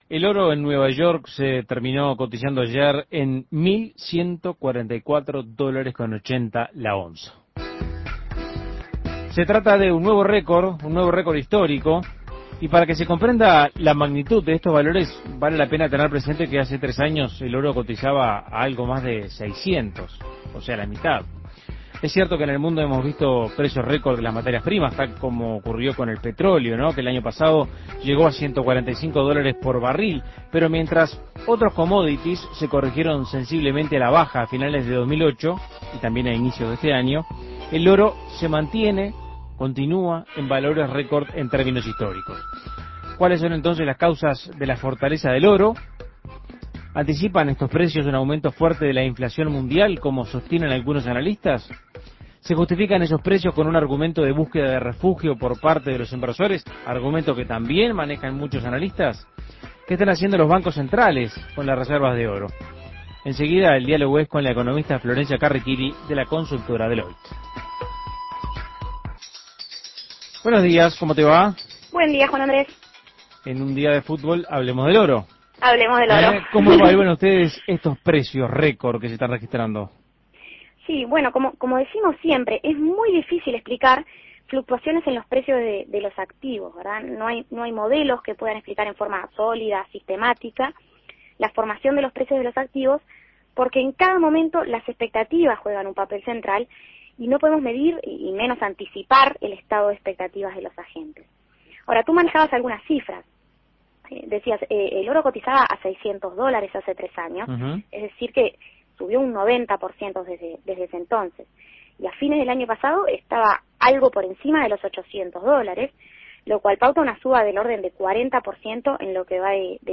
Análisis Económico Los precios del oro se aproximan a 1.150 dólares por onza, un nuevo récord histórico.